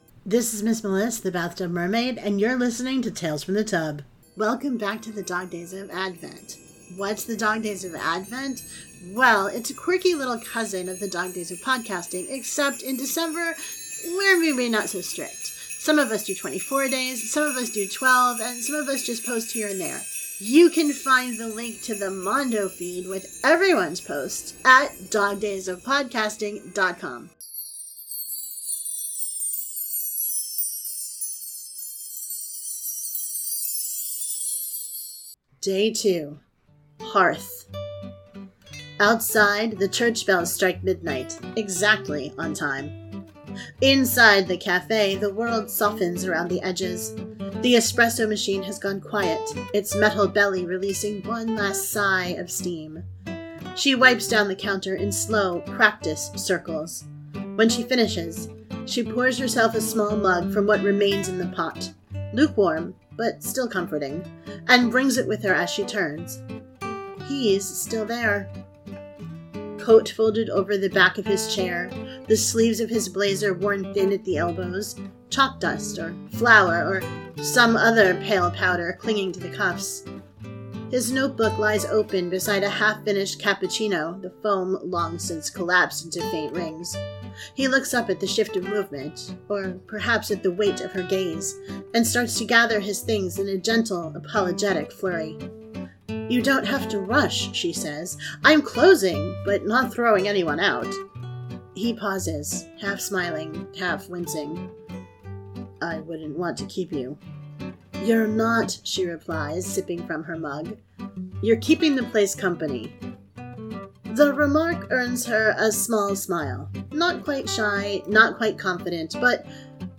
• Sound Effects and Music are from Freesound and UppBeat